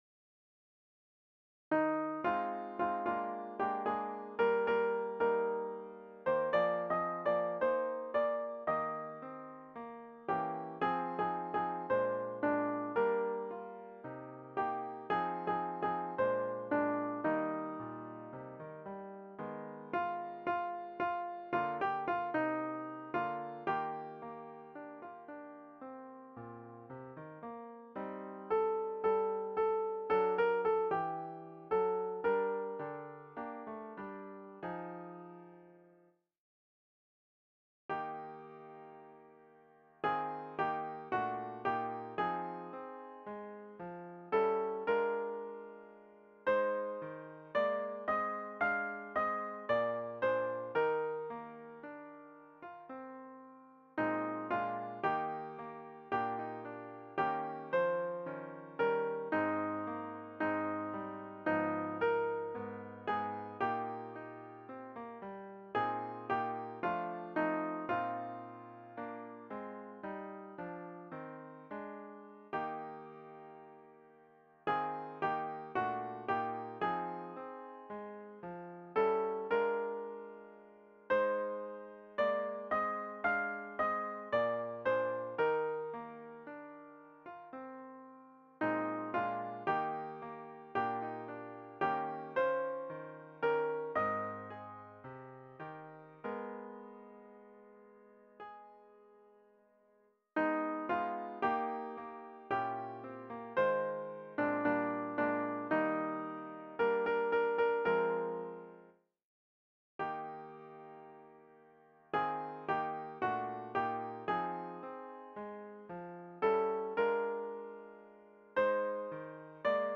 Fichiers pour répéter :
Noël blanc soprano